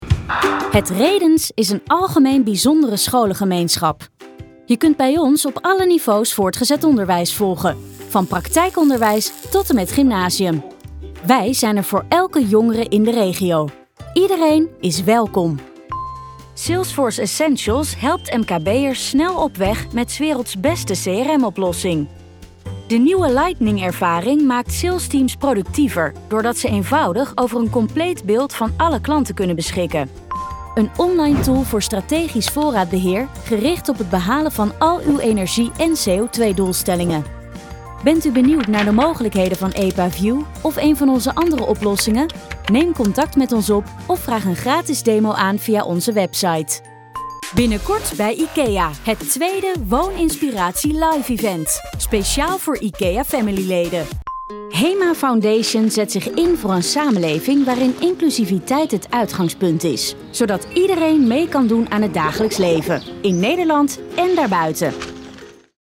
Corporate Videos
My voice sounds young, fresh and enthusiastic, but reliable.
Mic: Sennheiser MKH416